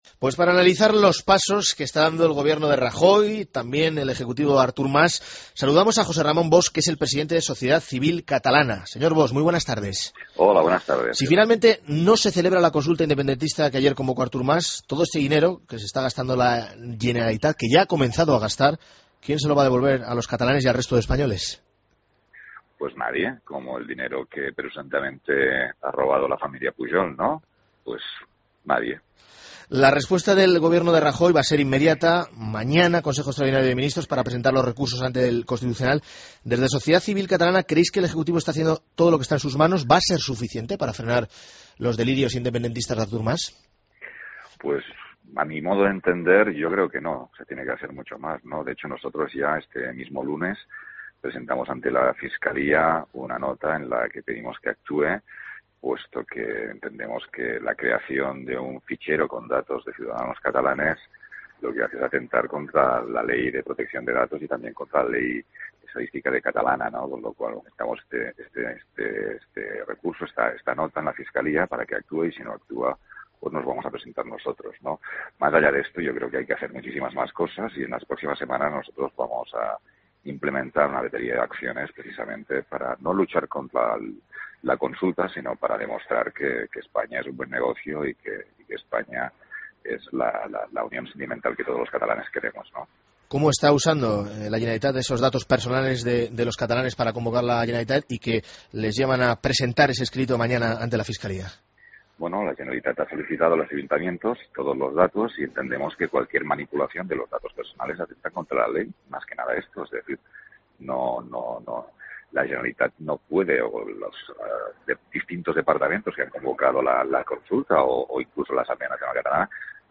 Entrevista Mediodía COPE